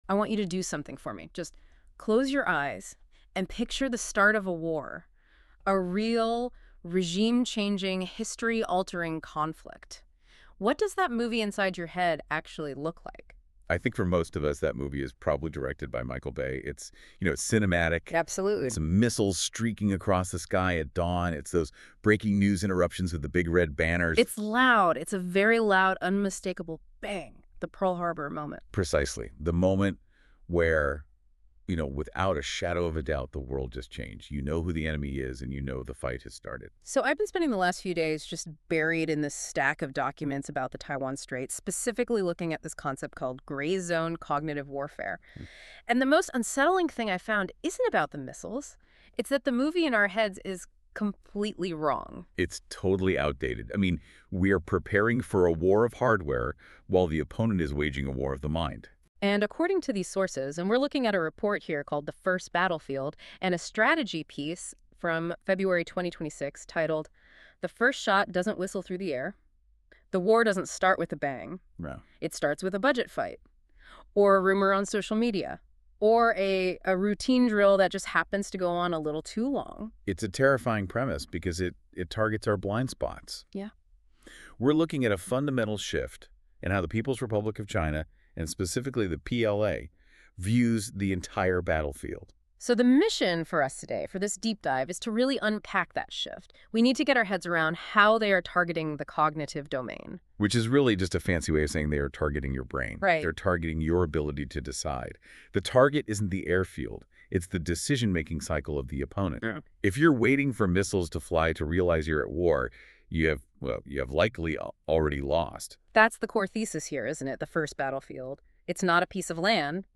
These podcast episodes are produced with AI voice technology. While we strive for accuracy, please be aware that the voices and dialogue you hear are computer-generated.